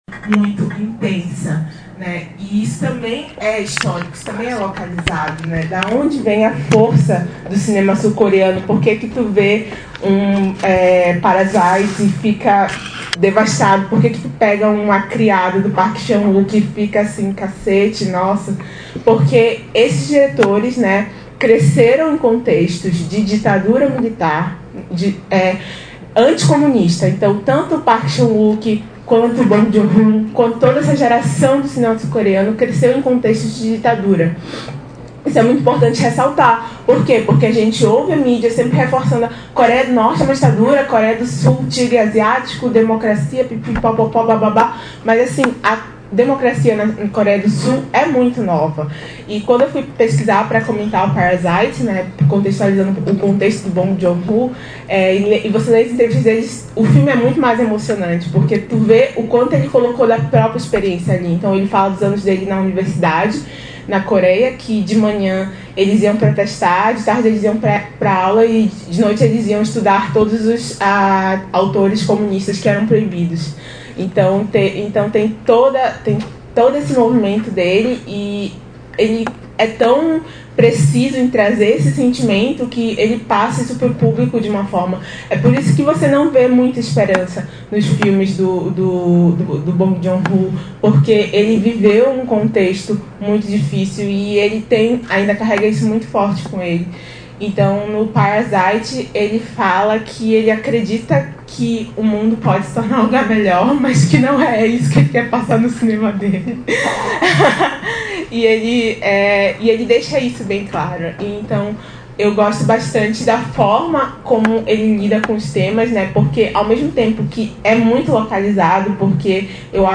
Nesta seção você encontrará os áudios dos comentários realizados sobre cada um dos filmes apresentados nas edições do Projeto Cinema Mundo.